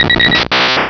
Cri_0084_DP.ogg